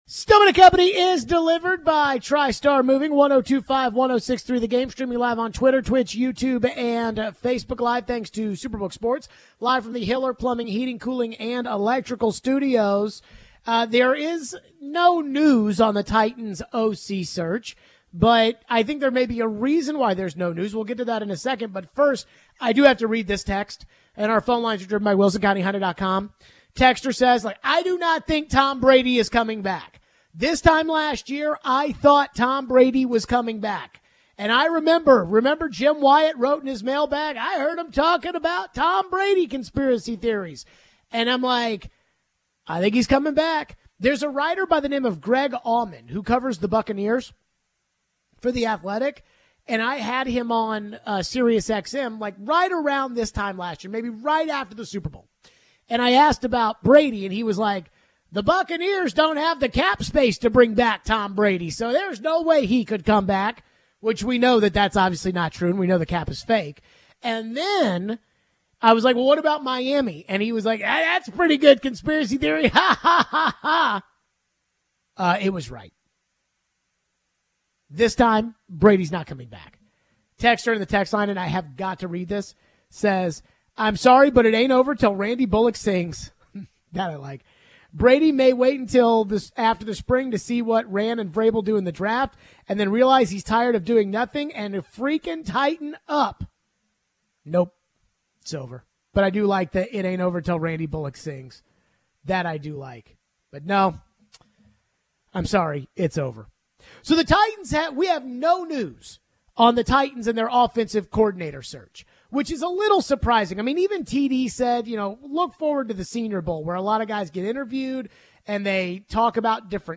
Would the Titans change the offensive system? We take your phones.